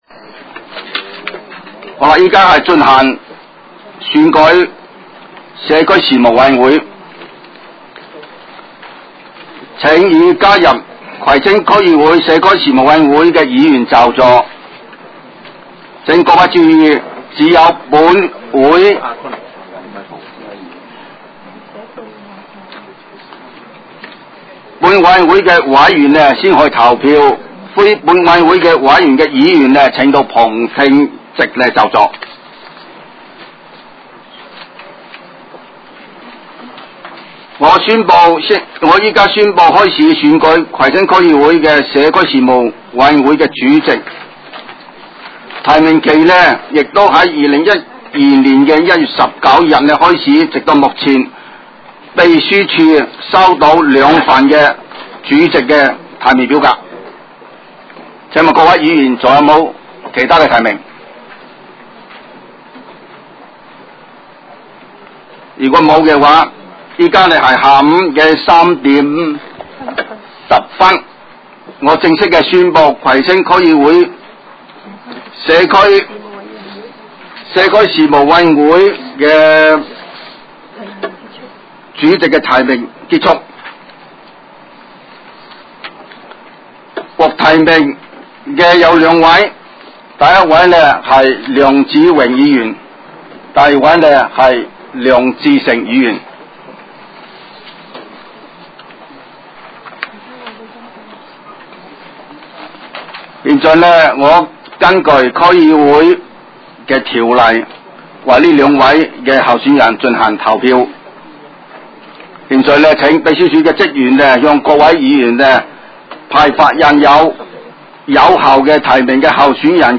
委员会会议的录音记录
地点: 香港葵涌兴芳路166-174号 葵兴政府合署10楼 葵青民政事务处会议室